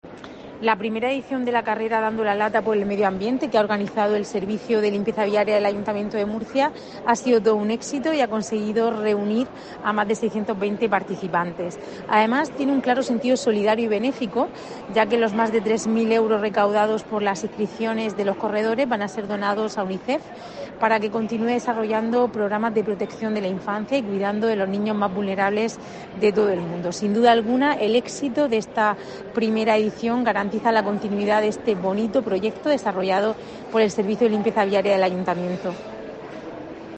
Rebeca Pérez, concejala de Fomento y Patrimonio